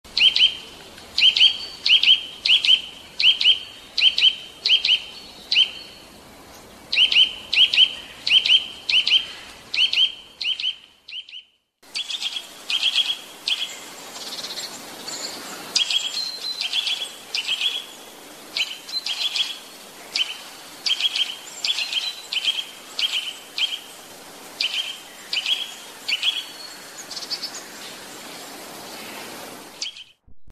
Kowalik - Sitta europaeus
Kowaliki odzywają się wieloma głosami.
szczebioty, oraz śpiew.
kowalik.mp3